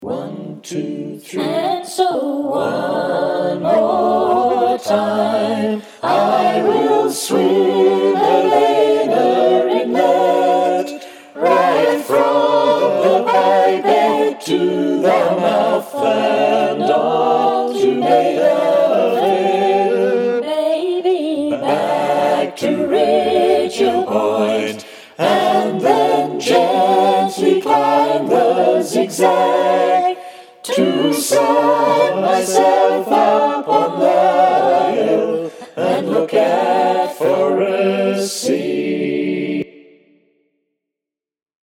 Forrest Sea Chorus Full Chorus to sing against;
forrest-sea-chorus-full.mp3